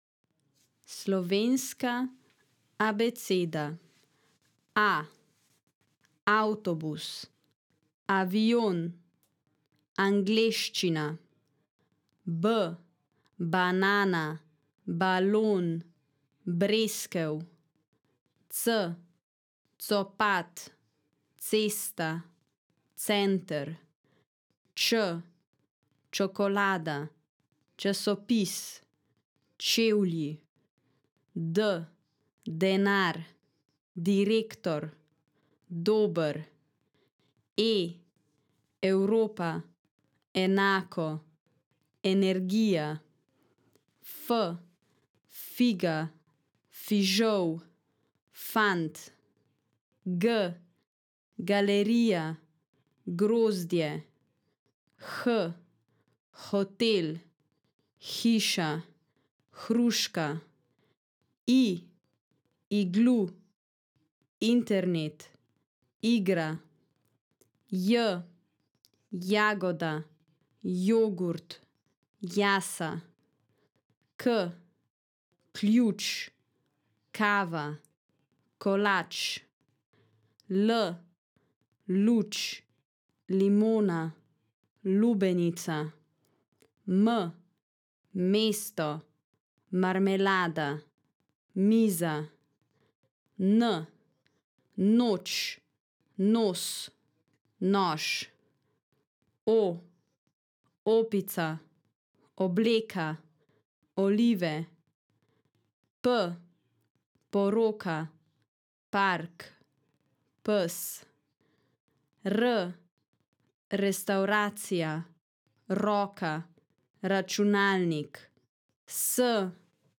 Listen to the Slovenian alphabet